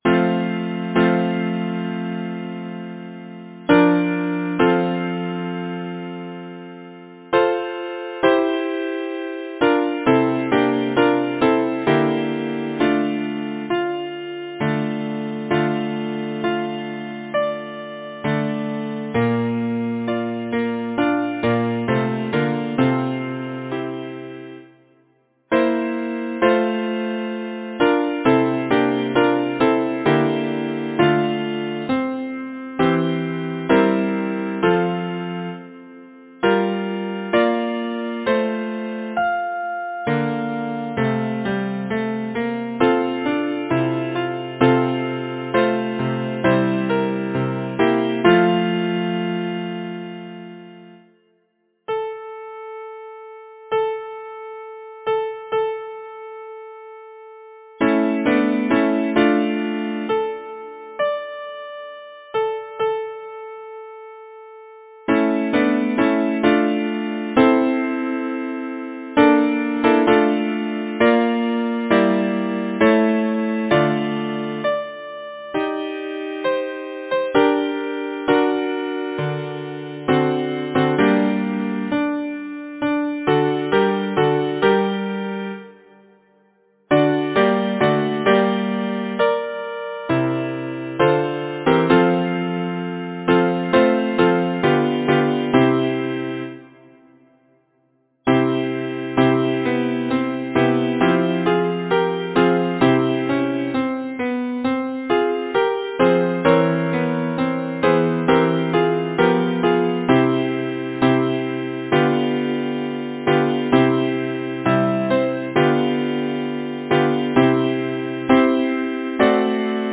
Title: Sweet Stream that winds through yonder glade Composer: William Sterndale Bennett Lyricist: William Cowper Number of voices: 4vv Voicing: SATB Genre: Secular, Partsong
Language: English Instruments: A cappella